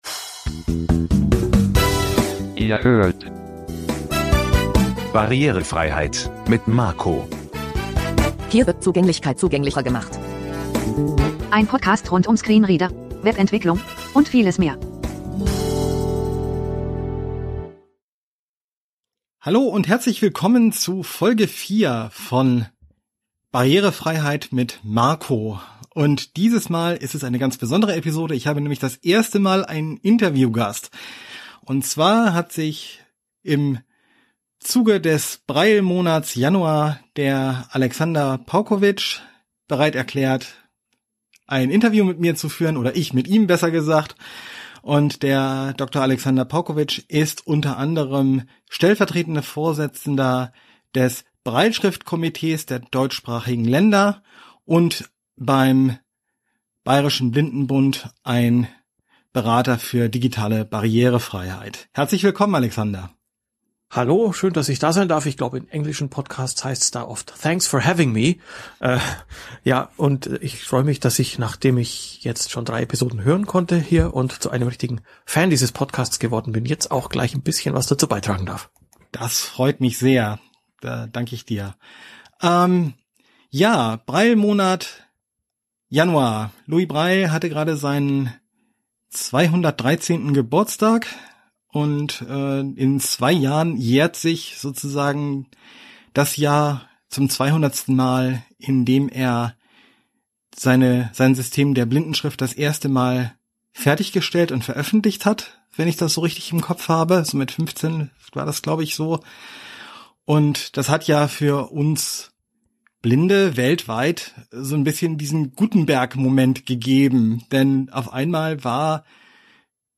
Unser Gespräch deckt ein breites Spektrum von Themen ab. Es kommen die Historie, Entwicklung, Bedeutung in Freizeit, Schule und Beruf und auch Herausforderungen zur Sprache, die die Brailleschrift immer wieder im digitalen Wandel erfährt. Aber es geht auch um Chancen und neue Möglichkeiten, die genau diese Digitalisierung für die Brailleschrift bietet.